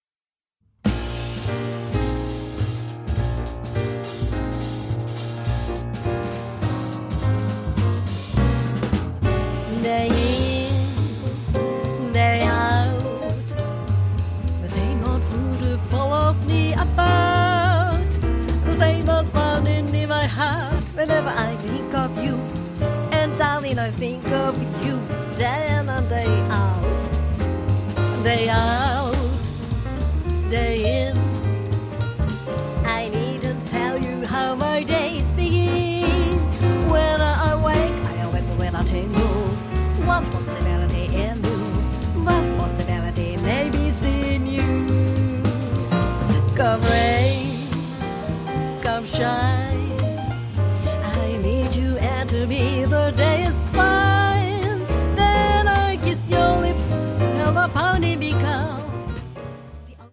2018年1月15,16日　前橋市・夢スタジオで録音